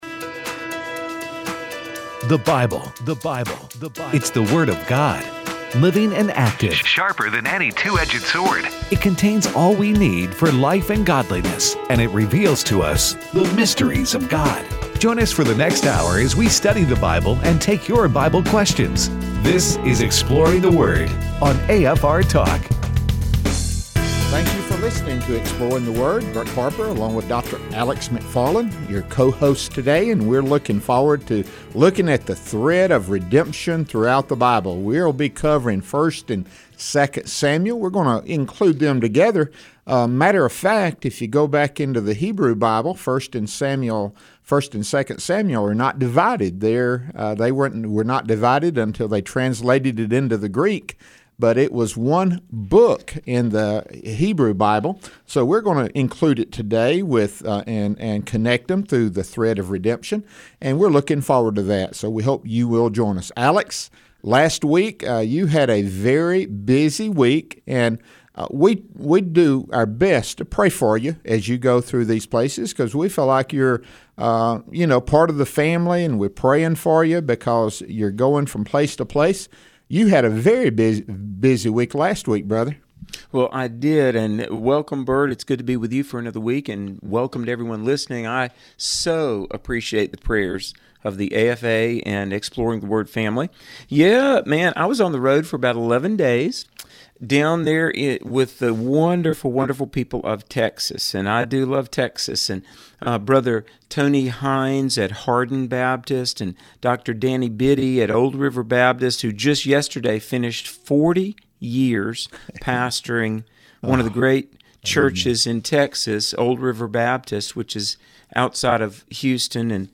Here, they discuss 1st & 2nd Samuel and take your phone calls.